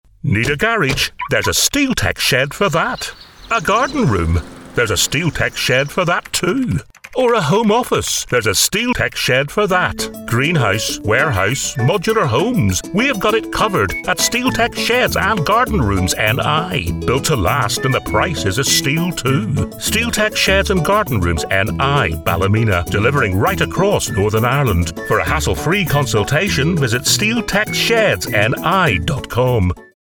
Anglais (Irlandais)
J'ai un accent nord-irlandais doux et engageant.
Chaleureux
Autoritaire
De la conversation